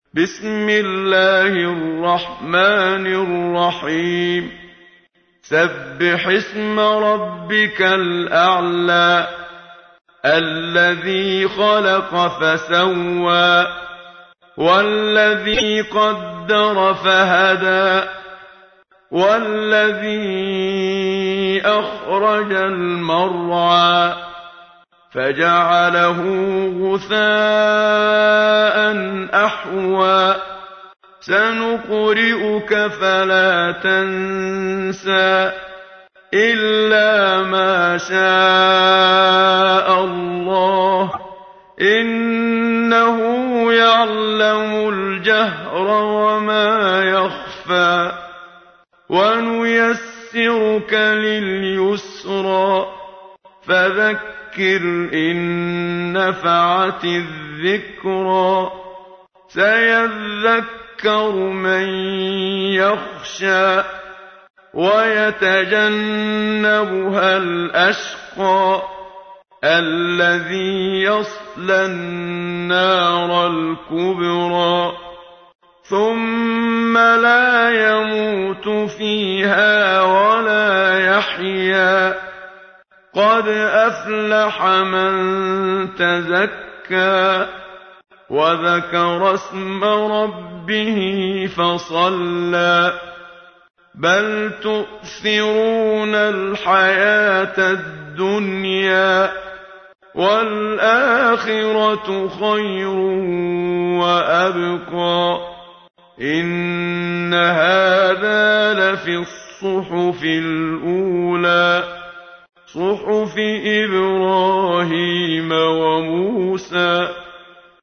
تحميل : 87. سورة الأعلى / القارئ محمد صديق المنشاوي / القرآن الكريم / موقع يا حسين